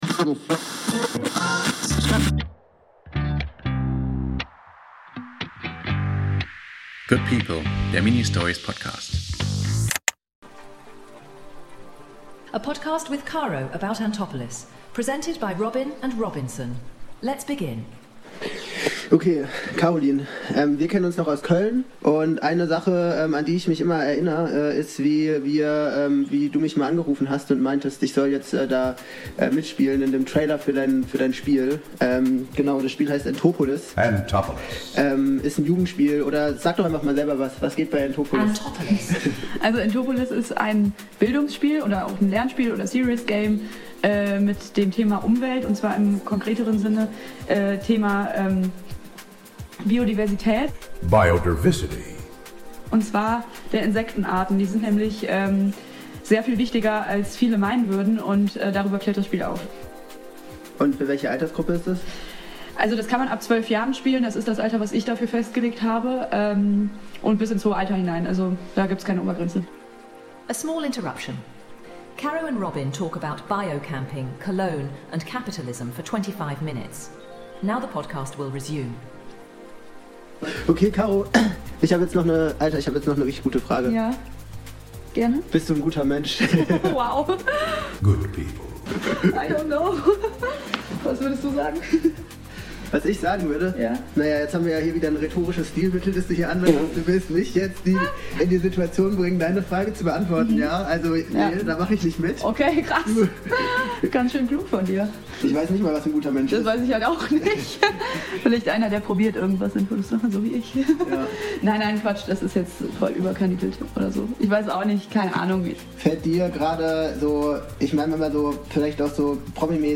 oder…?Es ist ein Gespräch voller Up und Downs, Lachern und Passagen, die bedenklich machen.